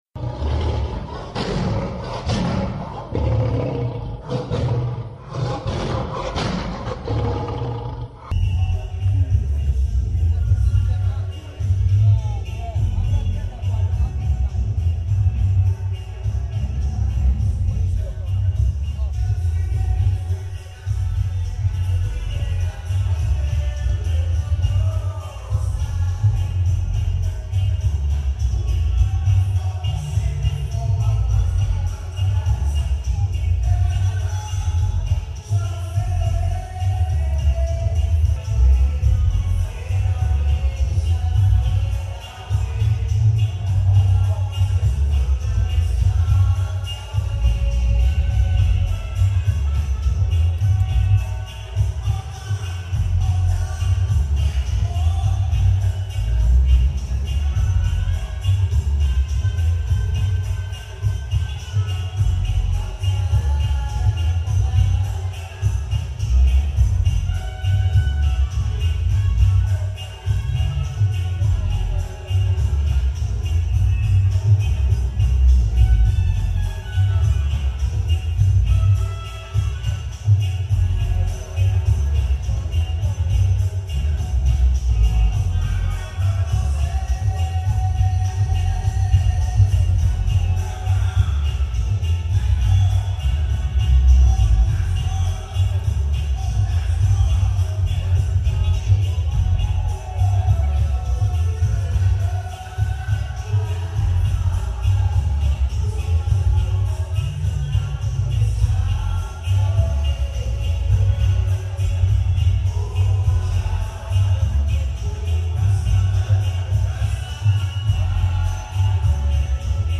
Godfather of DUB sound systèms